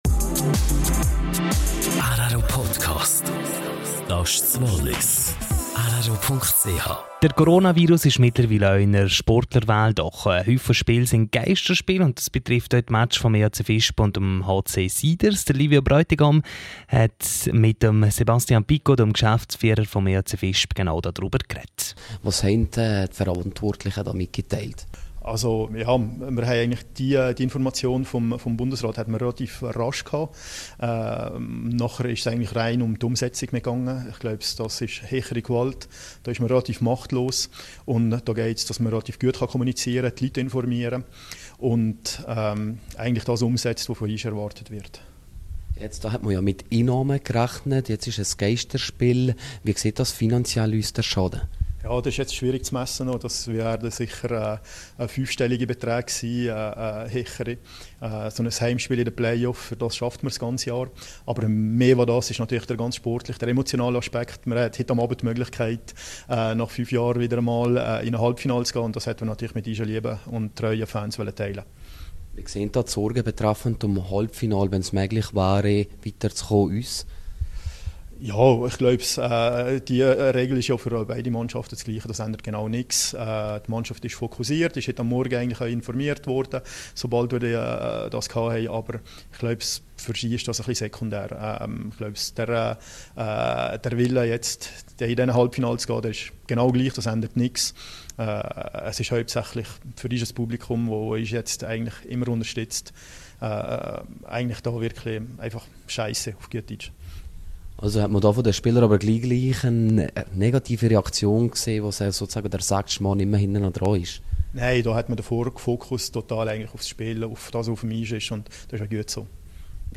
Geisterspiel EHC Visp - HC Thurgau. Interview